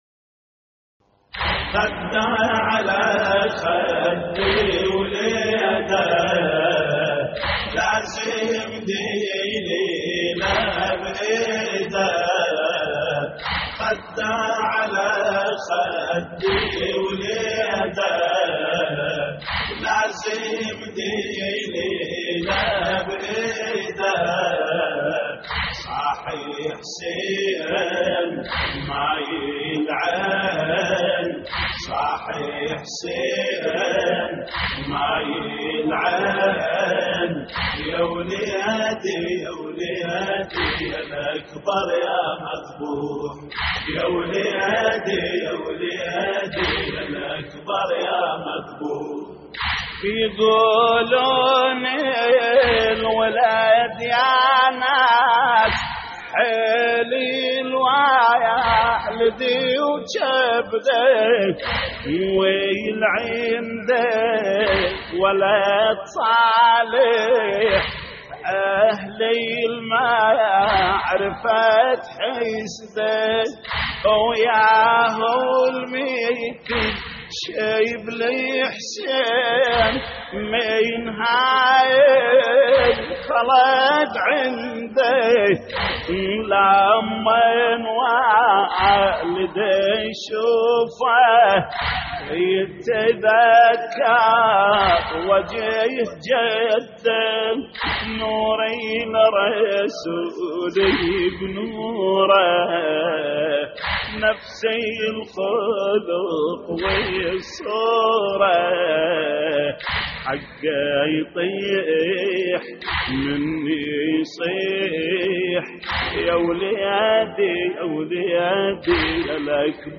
تحميل : خده على خد وليده لازم دليله بإيده صاح حسين ماي العين / الرادود جليل الكربلائي / اللطميات الحسينية / موقع يا حسين